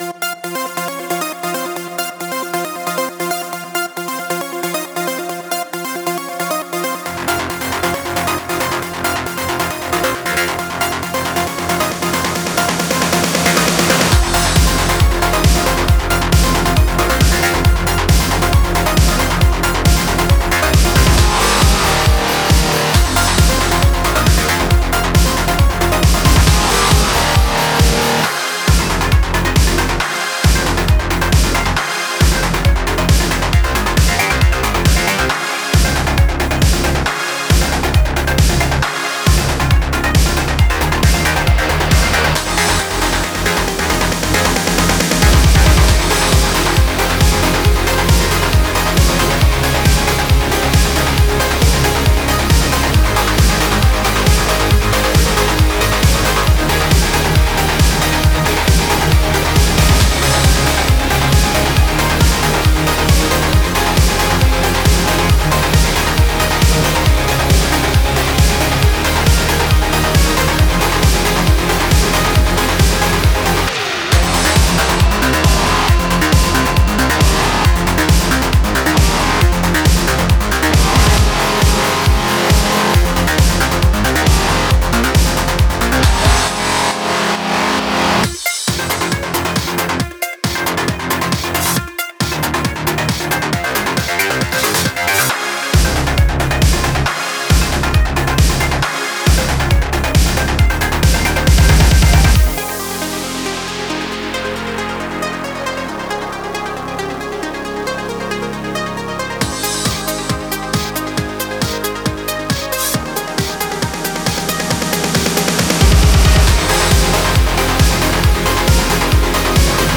Genre: Synthwave.